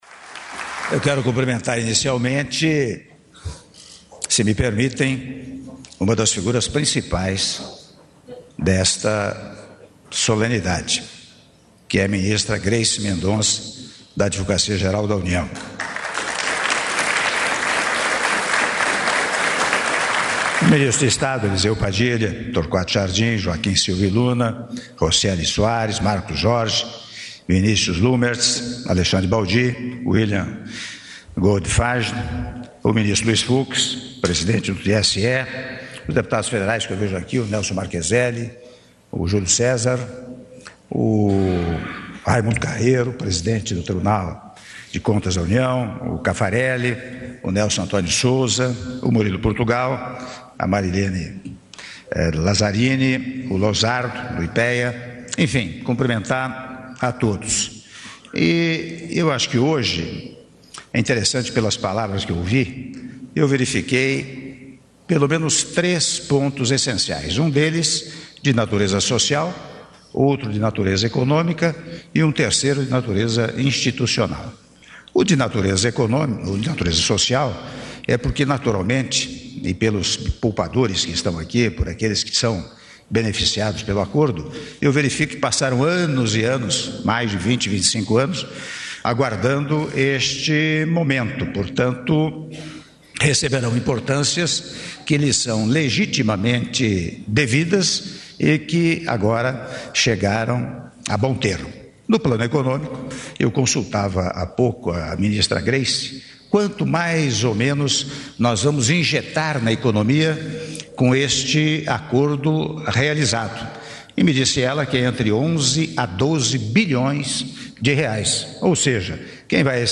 Áudio do discurso do Presidente da República, Michel Temer, durante o Lançamento da Plataforma de Adesão aos Planos Econômicos -Brasília/DF- (08min10s)